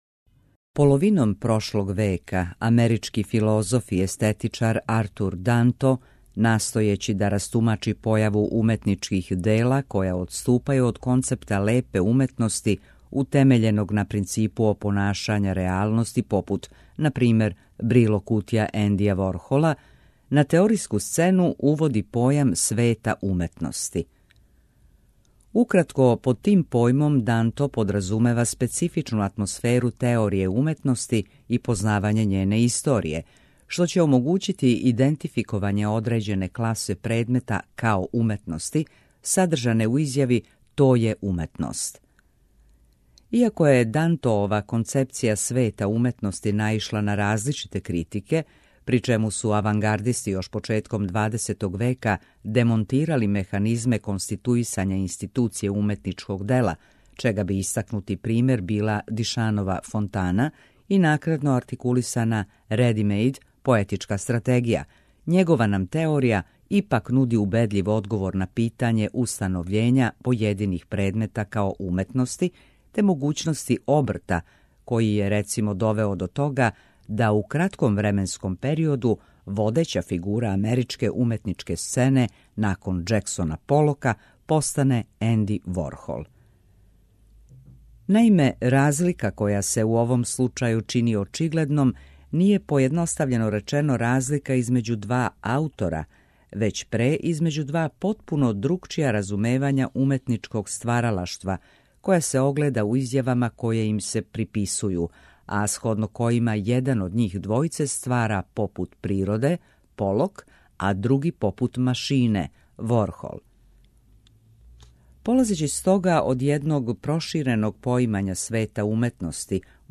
Прва говорна емисија сваке вечери од понедељка до петка.